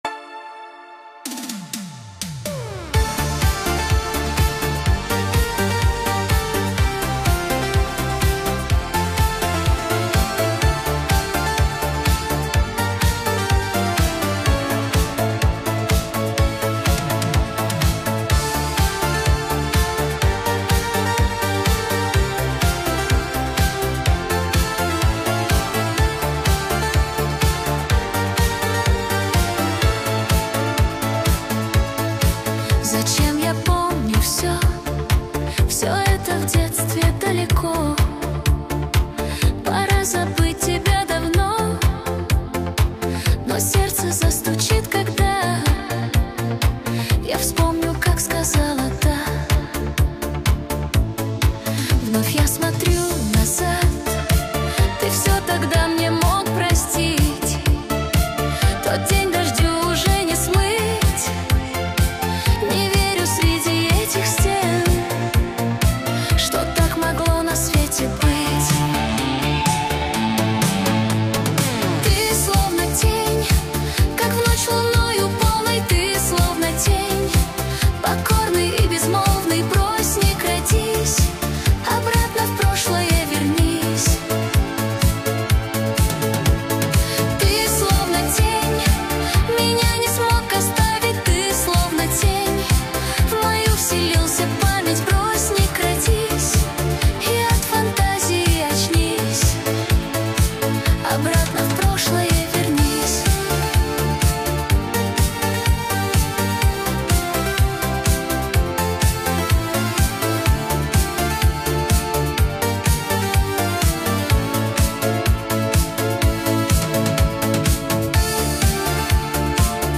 • Качество: 320 kbps